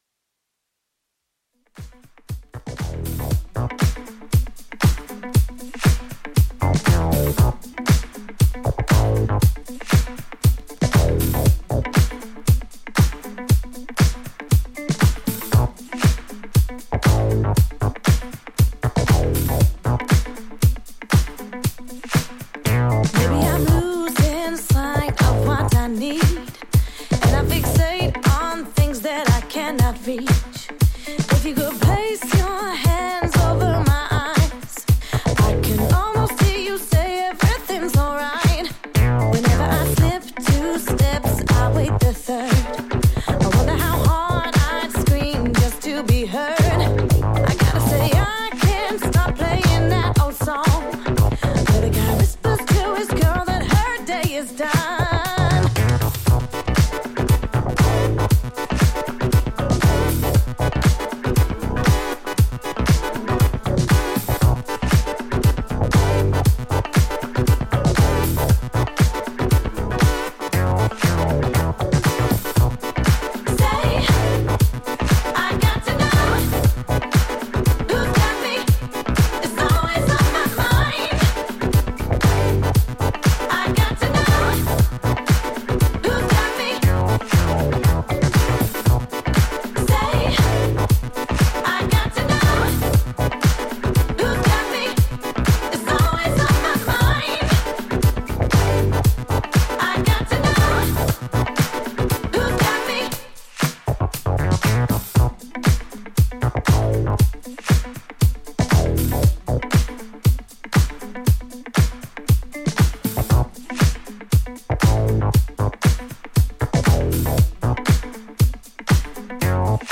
ジャンル(スタイル) NU DISCO